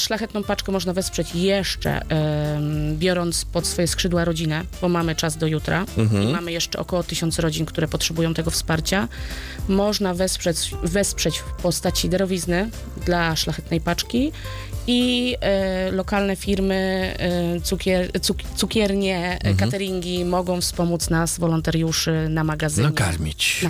To nie oznacza, że nie można jeszcze pomóc. Wolontariuszka tłumaczy jak to zrobić.